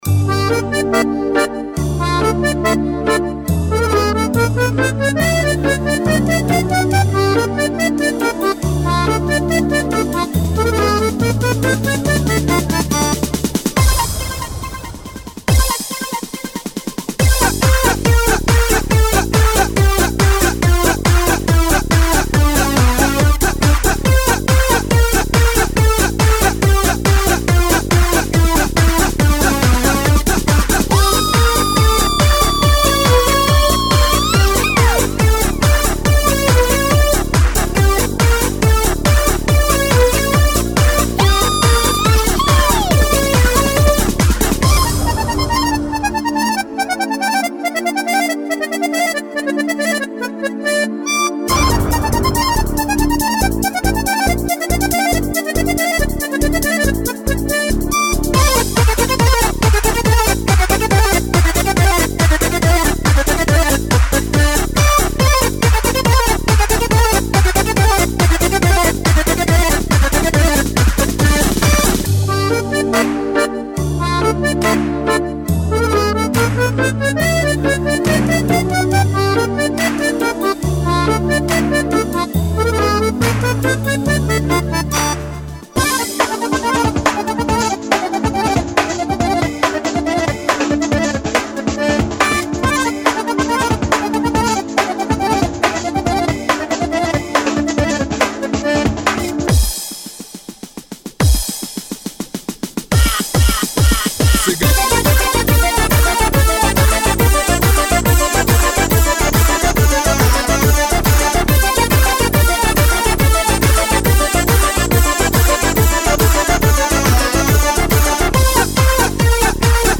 Народные